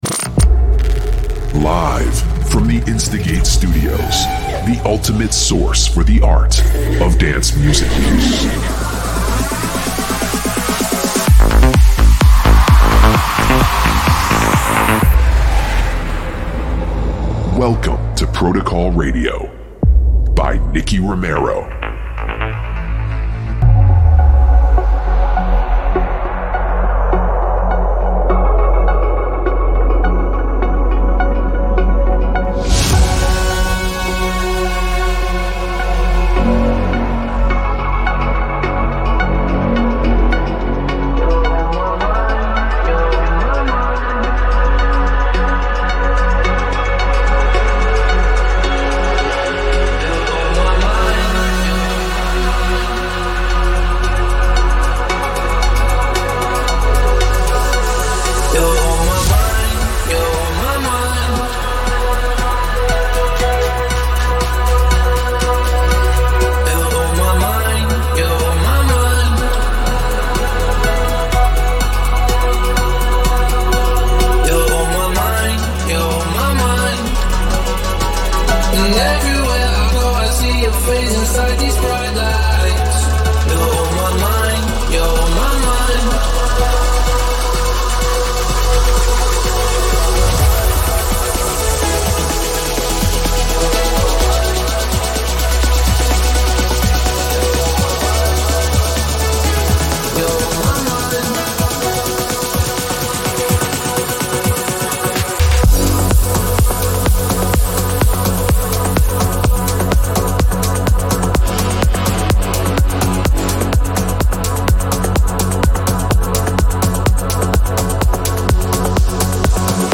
special 2025 Year mix